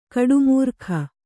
♪ kaḍumūrkha